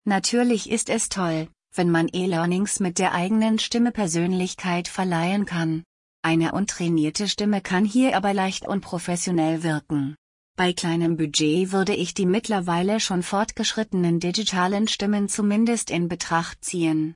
Hörprobe von Polly?
Hmm ... das klingt schon erstaunlich gut. Bloß beim Wort Digital wird es komisch.